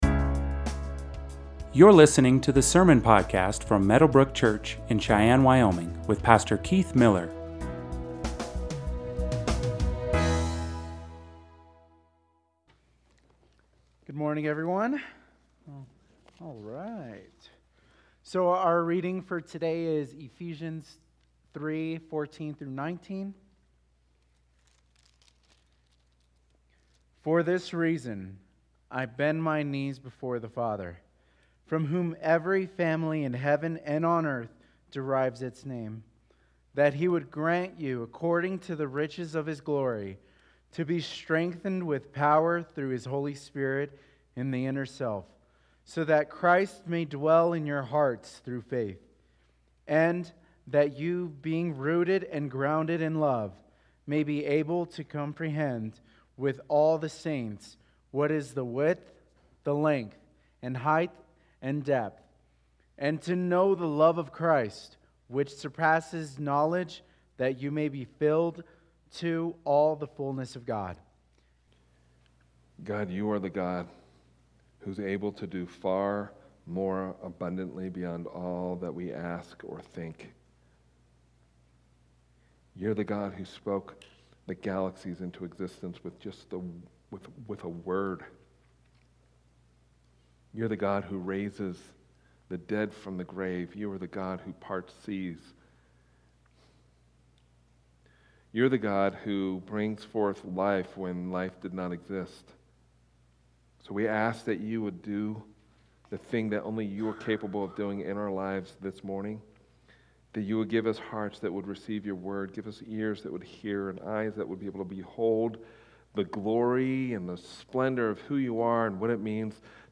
Sermons | Meadowbrooke Church